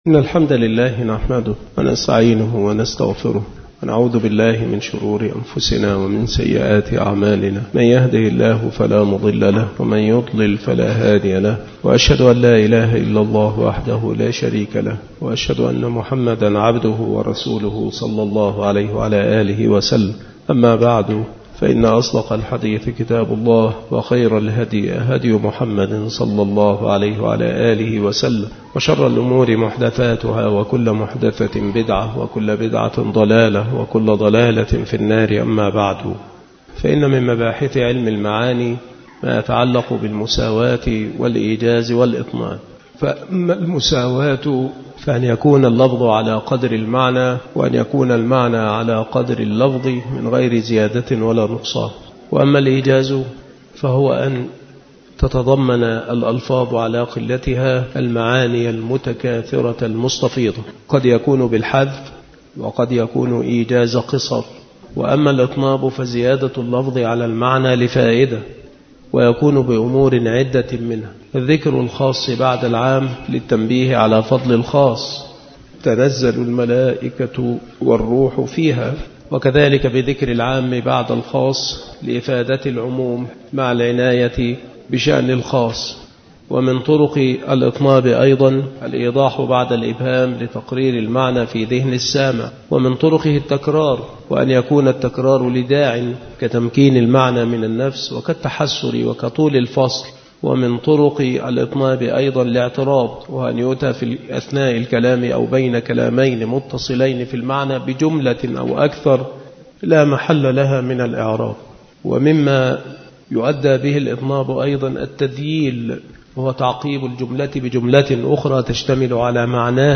مكان إلقاء هذه المحاضرة بالمسجد الشرقي بسبك الأحد - أشمون - محافظة المنوفية - مصر عناصر المحاضرة : تمرينات على الإطناب. من طرق الإطناب. أثر علم المعاني في بلاغة الكلام.